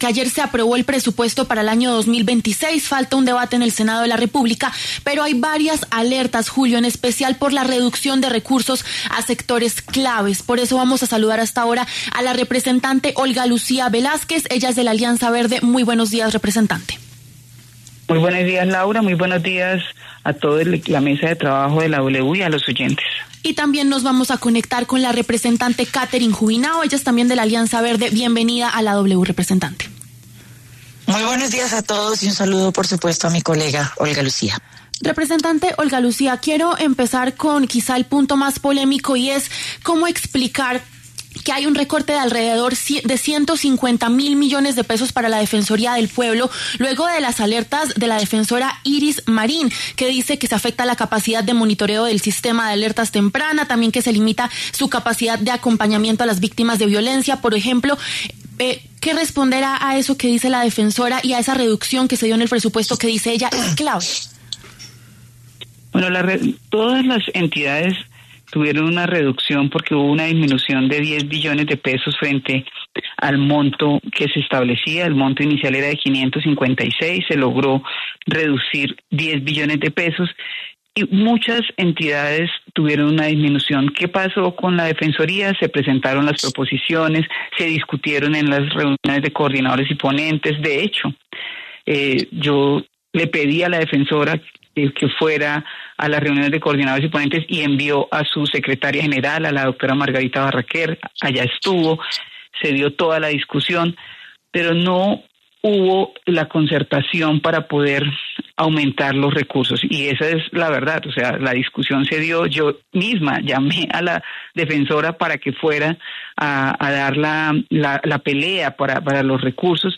Polémica reducción al presupuesto de la Defensoría: Debaten Olga Lucía Velásquez y Catherine Juvinao
Las representantes Olga Lucía Velásquez y Catherine Juvinao, de la Alianza Verde, pasaron por los micrófonos de La W. Hablaron sobre la reducción de recursos a la Defensoría del Pueblo.